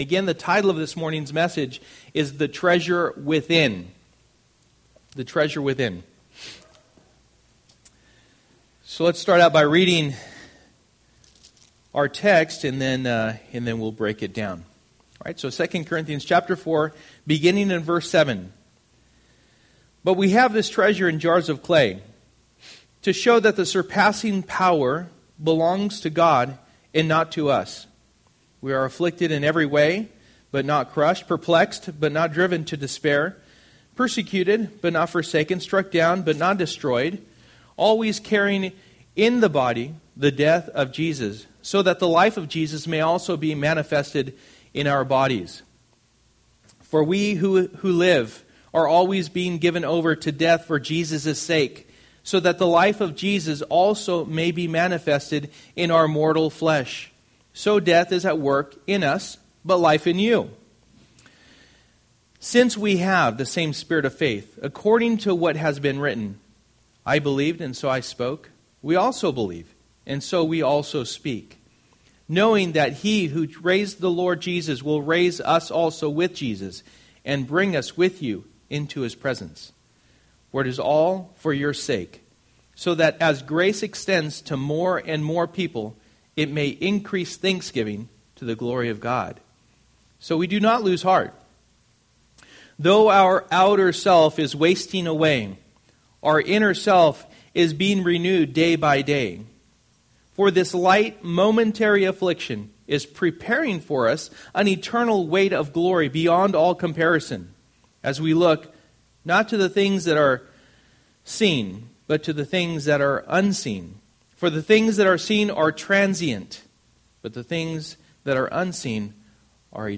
Generous Passage: 2 Corinthians 4:7-18 Service: Sunday Morning %todo_render% « Christmas Market